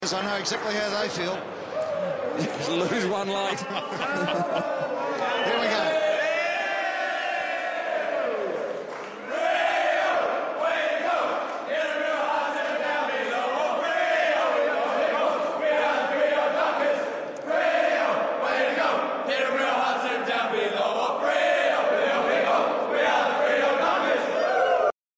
Listen to the players sing the team song after a two-point win over Richmond